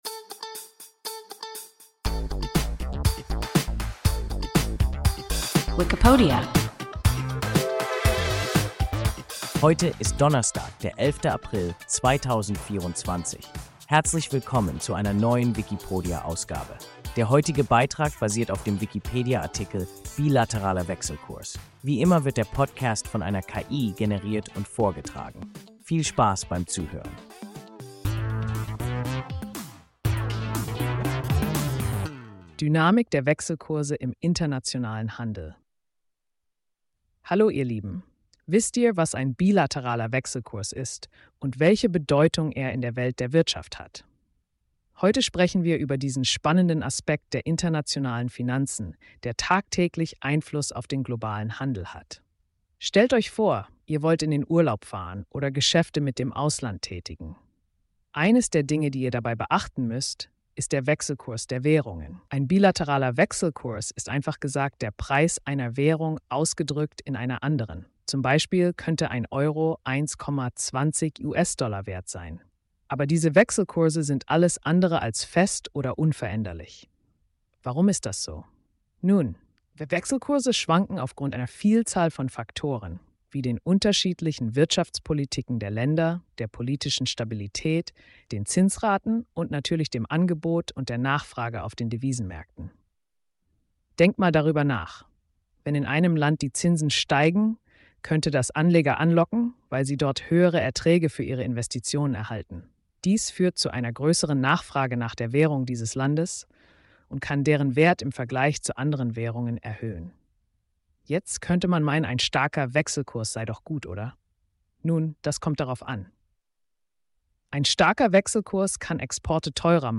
Wikipodia – ein KI Podcast